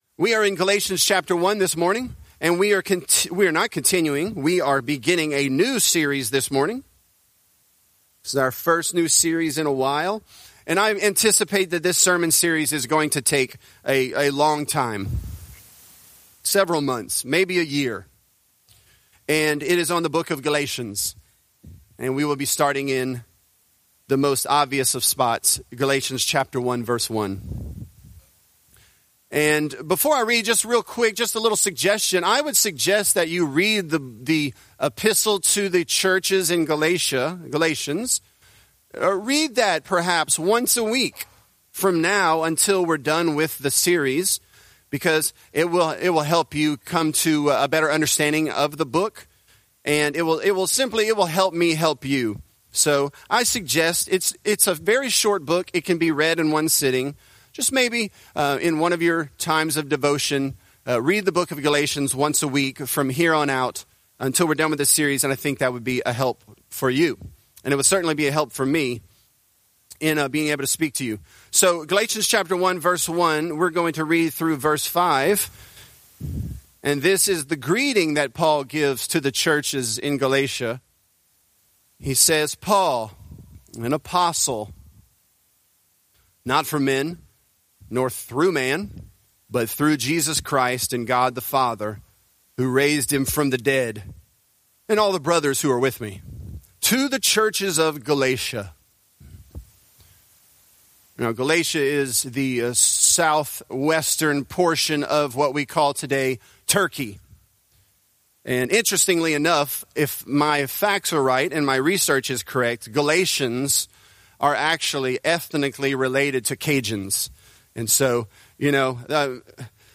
Galatians: Paul, An Apostle from God | Lafayette - Sermon (Galatians 1)